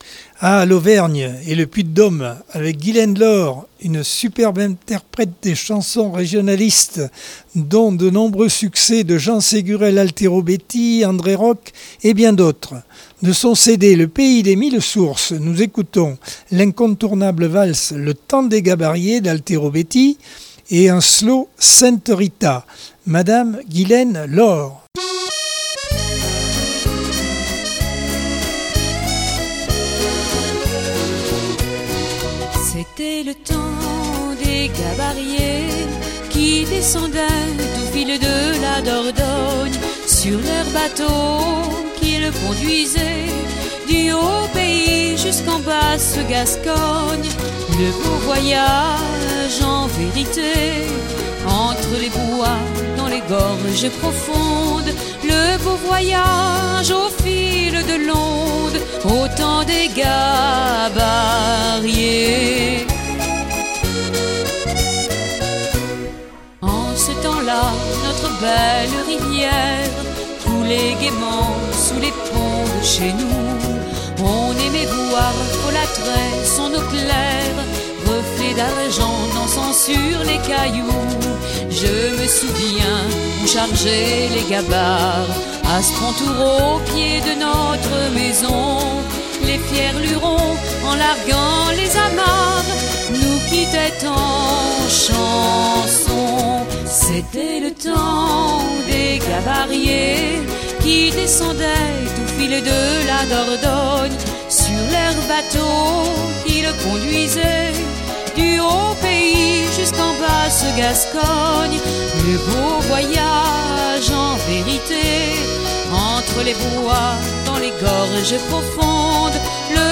Accordeon 2023 sem 21 bloc 2 - Radio ACX